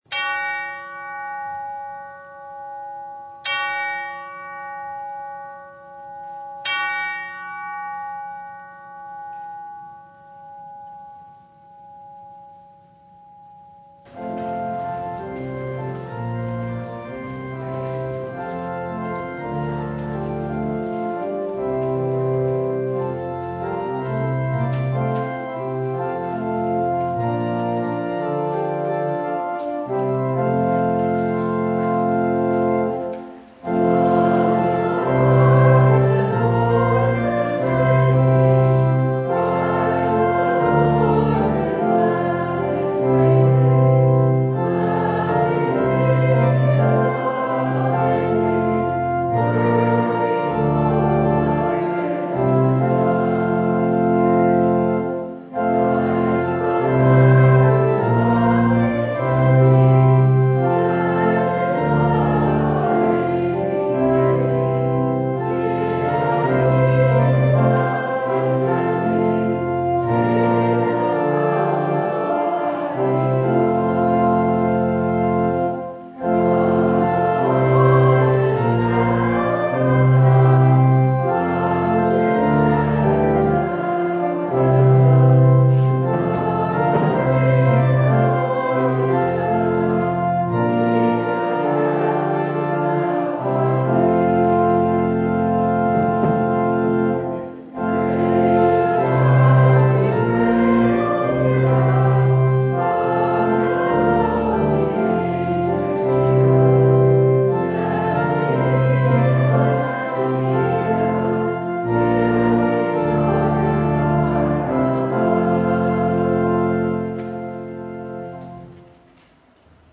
Service Type: Christmas Eve
Topics: Full Service